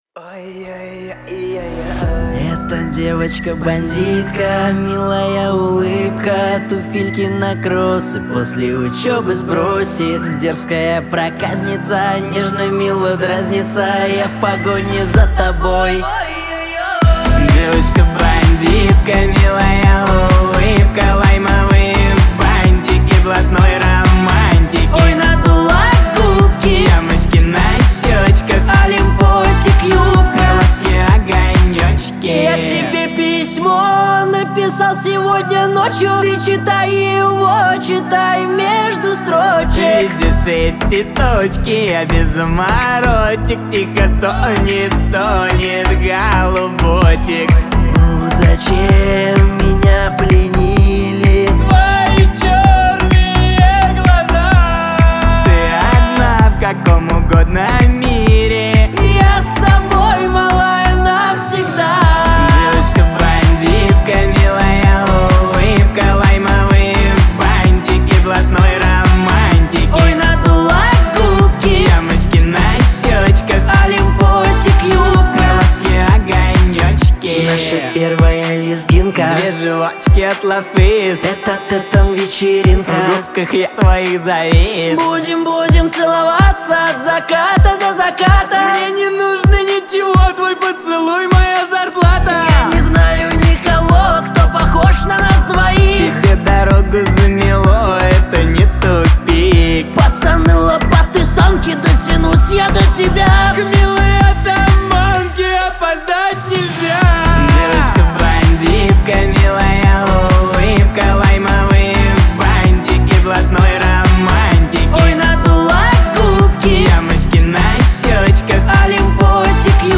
Танцевальная музыка